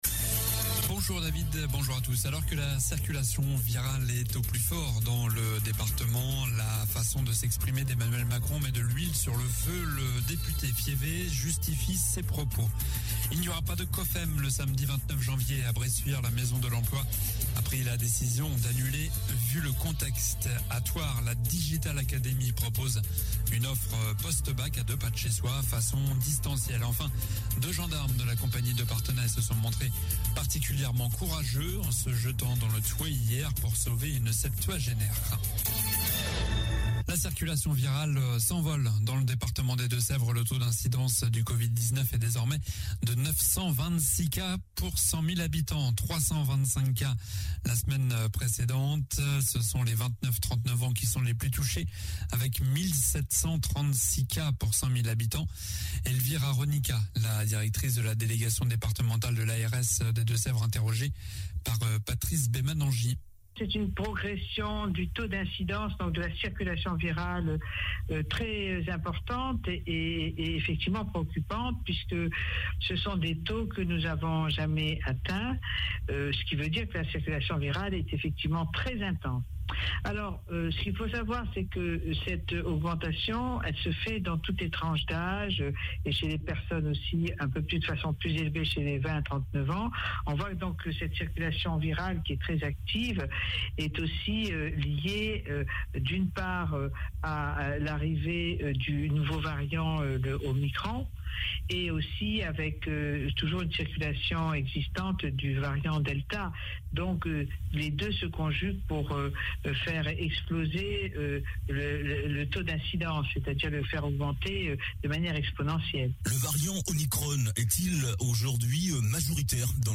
Journal du mercredi 05 janvier (midi)